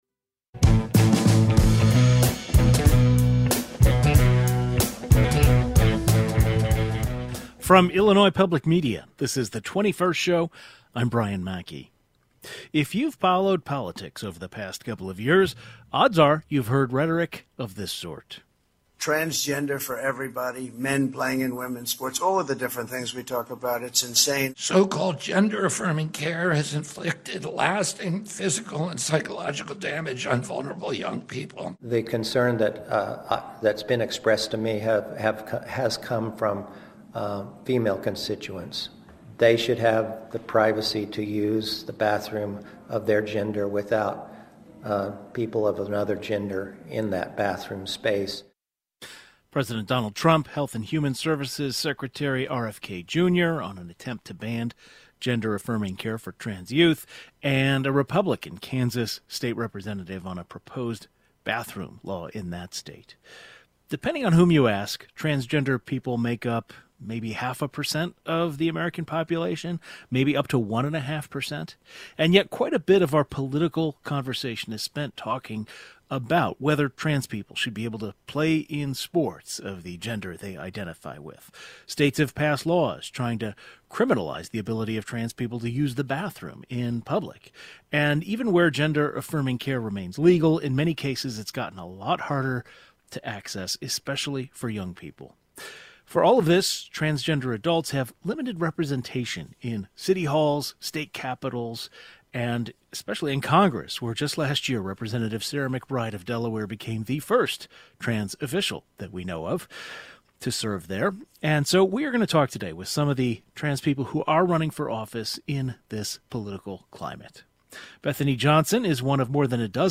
We’ll talk with several Midwestern trans women looking to change that by running for office themselves.